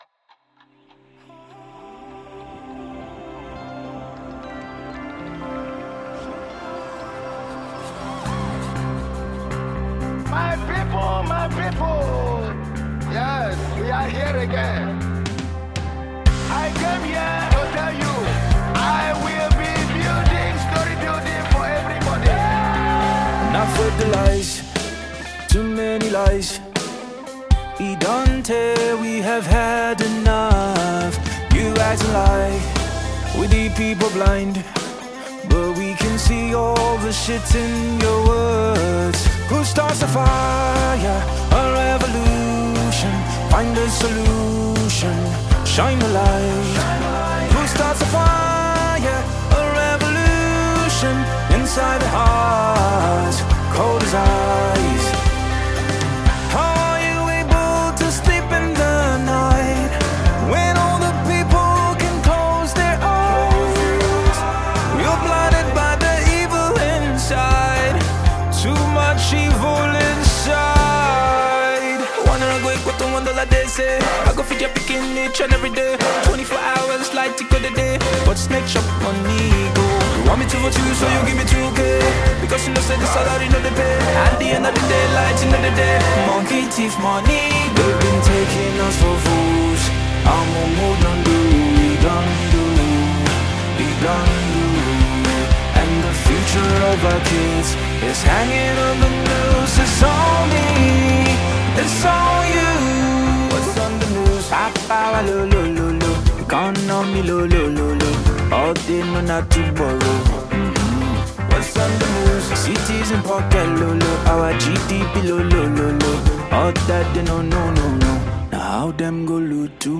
R&B and Soul singer